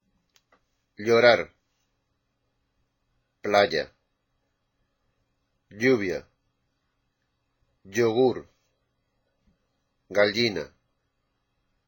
Listen to the following words which contain the letters "y" and "ll"
Most Spanish speakers pronounce the "y" (when it goes at the beginning or in the middle of a word) and the "ll" in a very similar way*